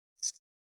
501,桂むき,大根の桂むきの音切る,包丁,厨房,台所,野菜切る,咀嚼音,ナイフ,調理音,
効果音厨房/台所/レストラン/kitchen食材